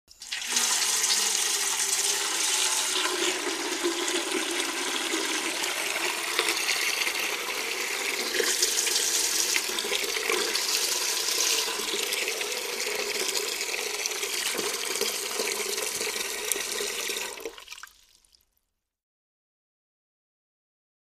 Tin Bucket: Fill With Water.